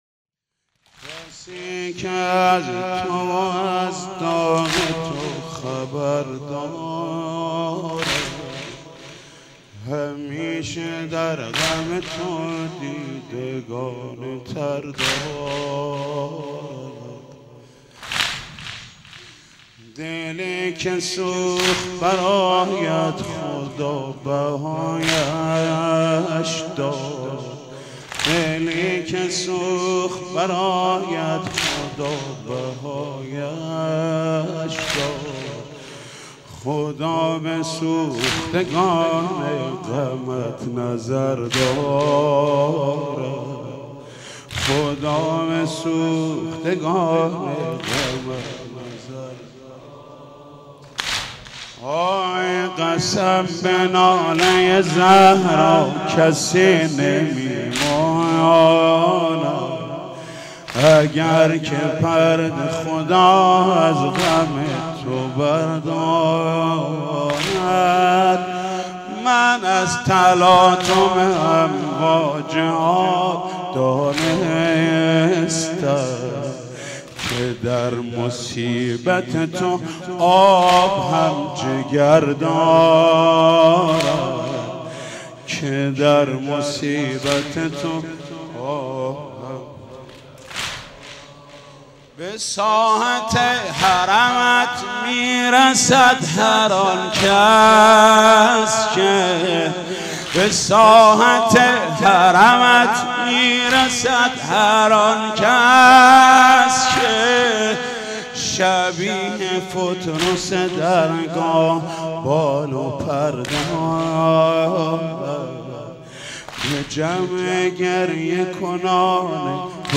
شهادت حضرت امام محمد باقر علیه السلام 1393 | مسجد حضرت امیر | حاج محمود کریمی
کسی که از تو و از داغ تو خبر دارد | واحد | حضرت امام محمد باقر علیه السلام